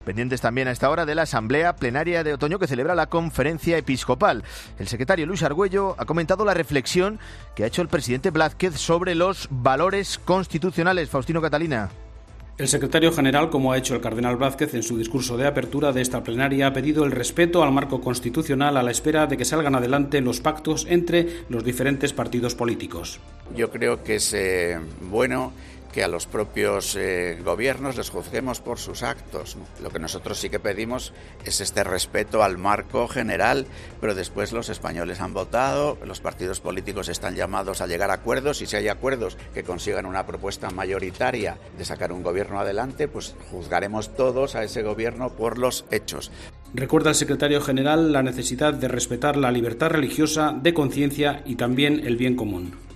En la puerta esperaban los periodistas mientras los prelados salían poco a poco. El secretario general, mons. Luis Argüello, se ha detenido para atenderles y responder a las preguntas sobre el discurso del presidente de la Conferencia Episcopal Española.